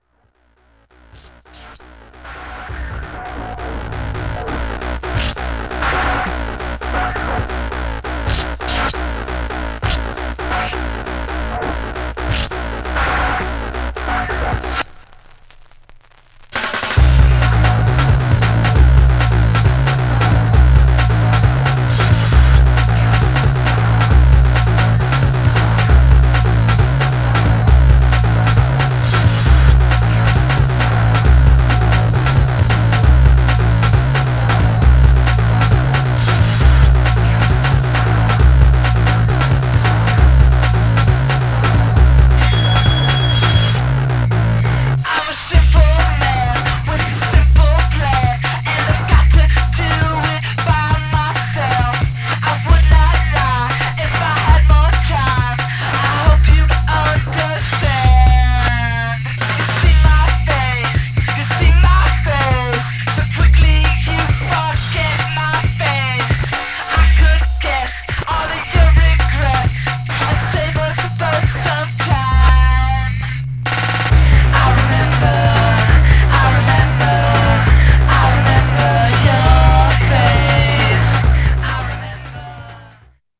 Styl: Techno, Breaks/Breakbeat